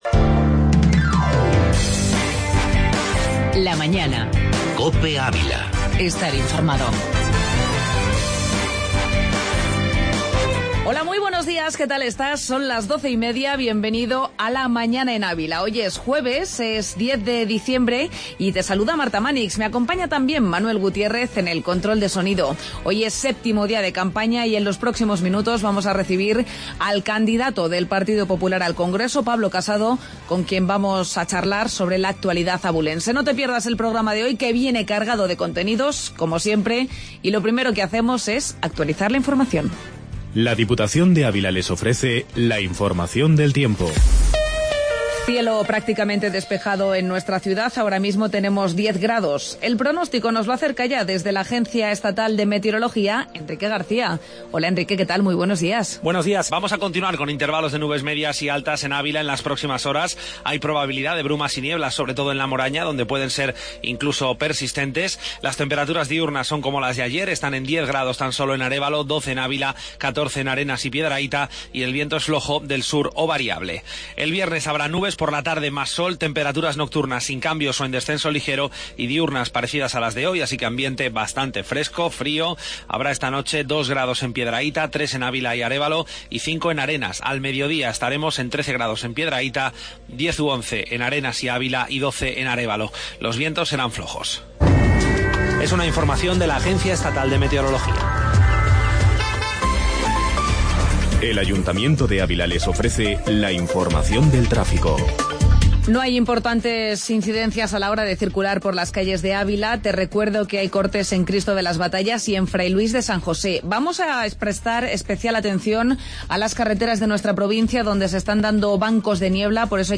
AUDIO: Entrevista Pablo Casado